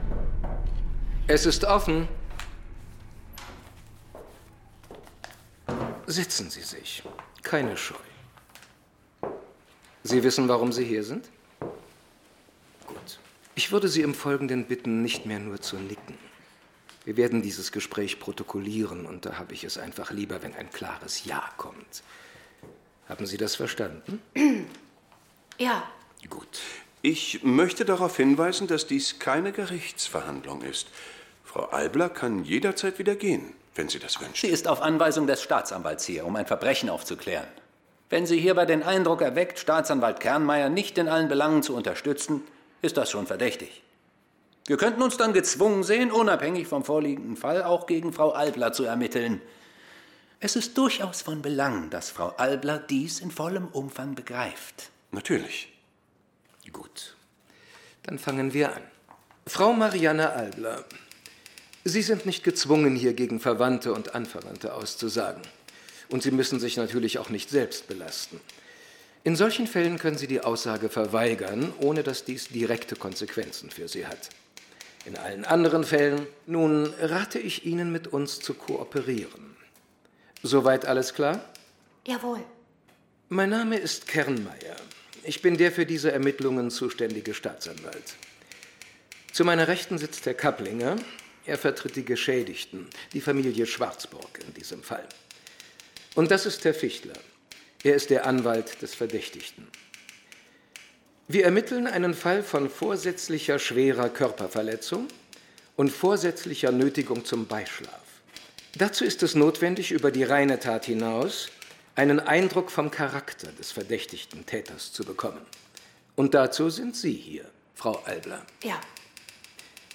Folge 4: Stimulus - Hörbuch